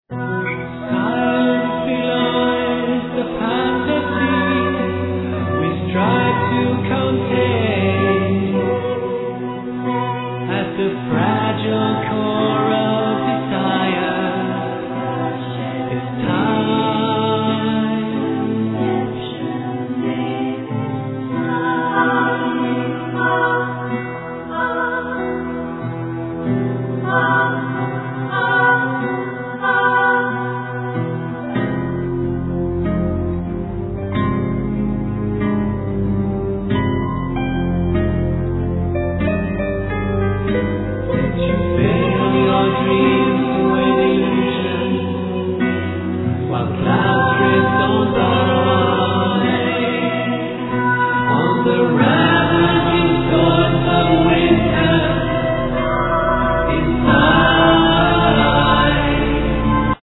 Violin
Flute
Drums, Percussions, Bass, Vocals ,All other instruments